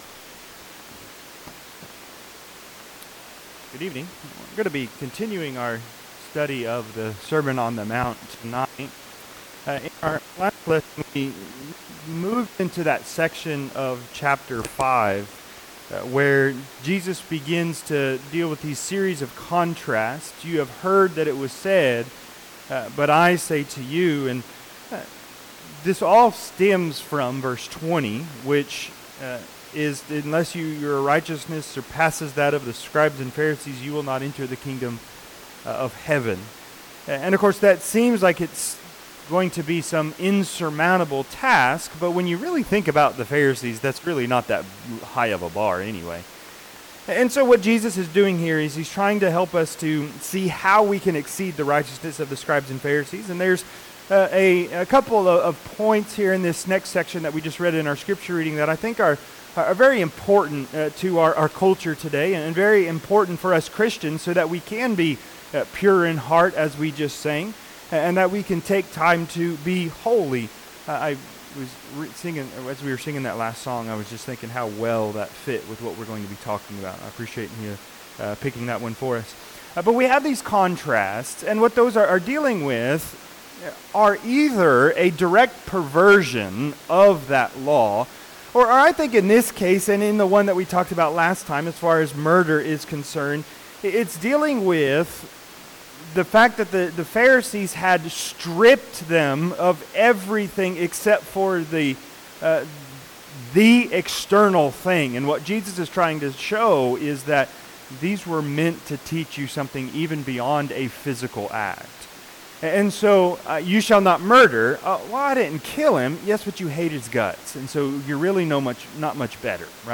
Matthew 5:27-30 Service Type: Sunday PM Discourse on Adultery of the Heart.